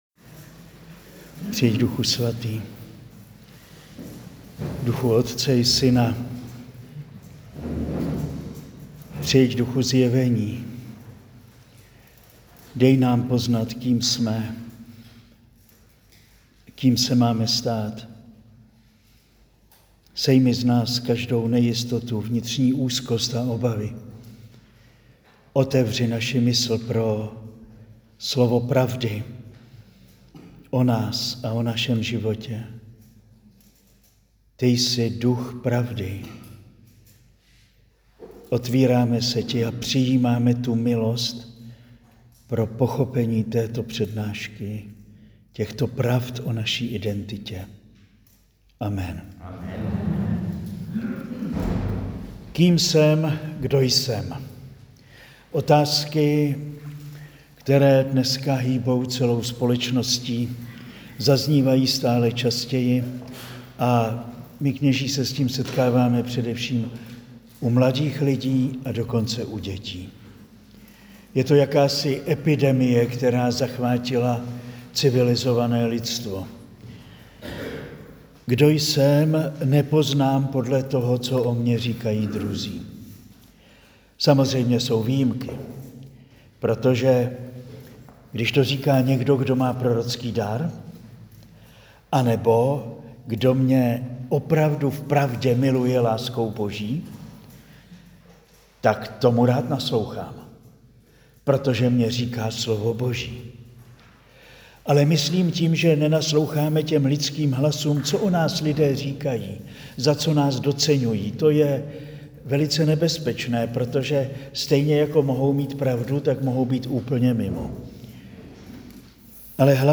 Nyní si můžete poslechnout třetí přednášku z pěti.
Přednáška zazněla na kurzu učednictví v květnu 2025